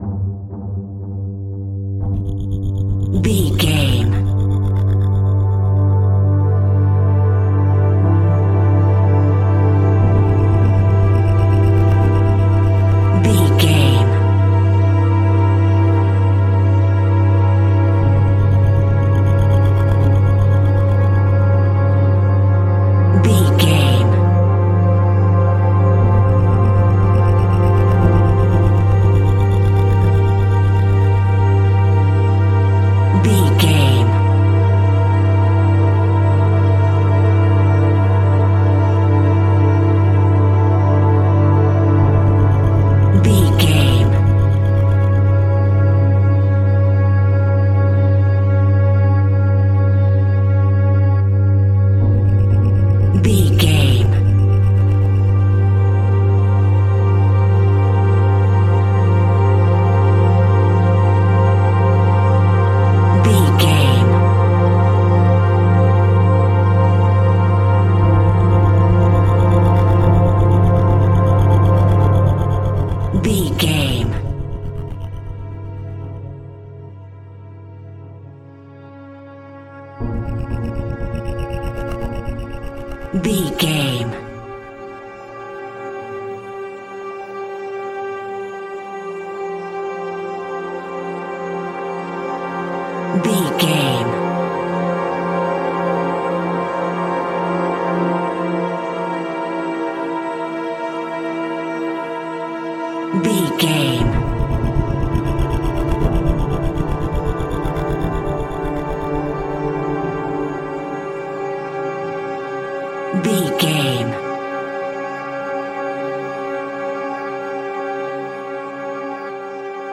Diminished
B♭
Slow
scary
ominous
dark
haunting
eerie
strings
synthesiser
ambience
pads